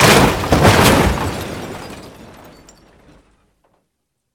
vehicleram.ogg